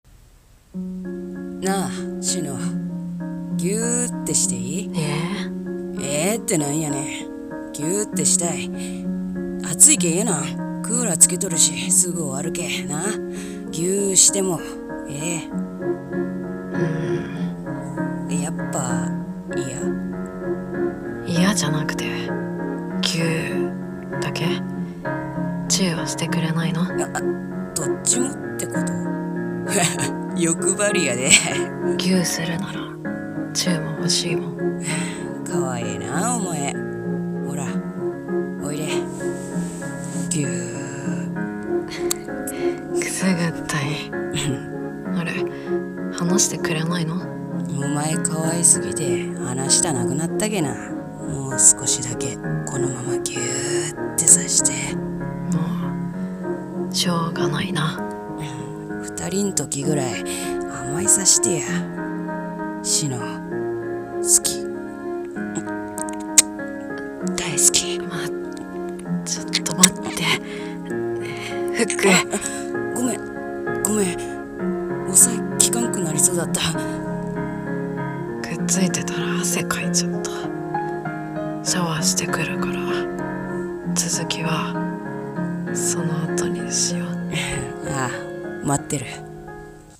【専コラ】ぎゅーっ……の、その先【声劇台本】